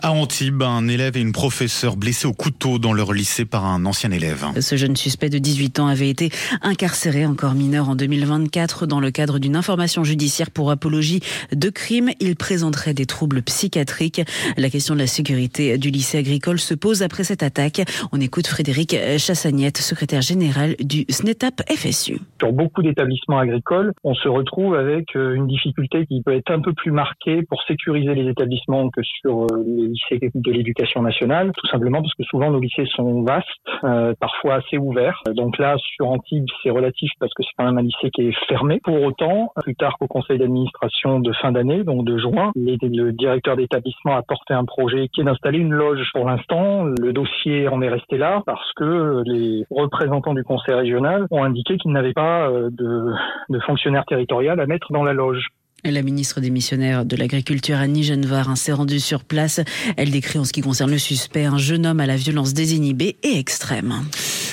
Le journal de 5h